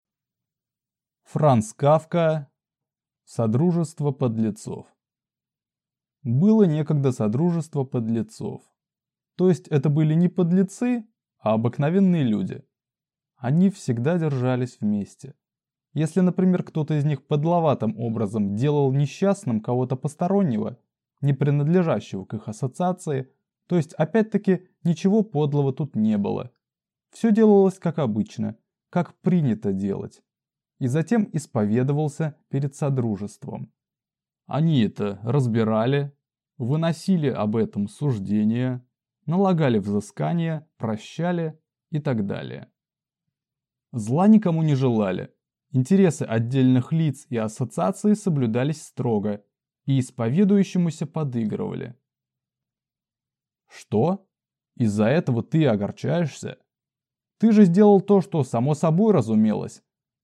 Аудиокнига Содружество подлецов | Библиотека аудиокниг